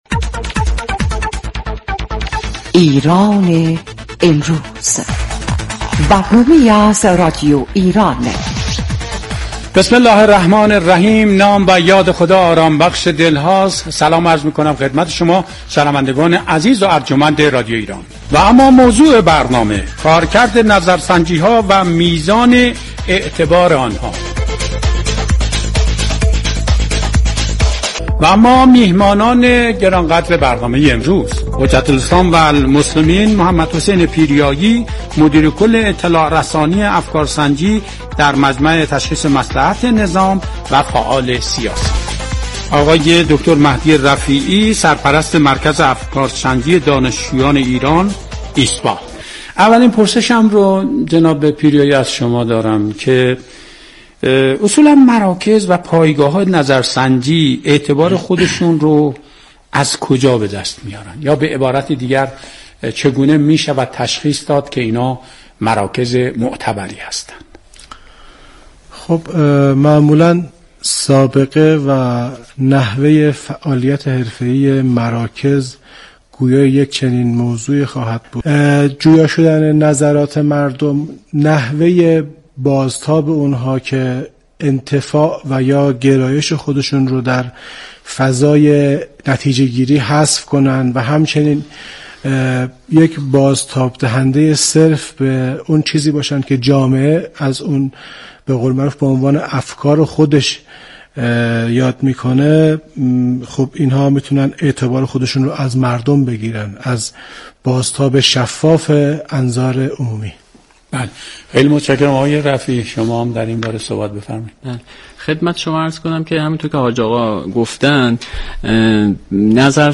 در بخش دیگر این گفت و گوی رادیویی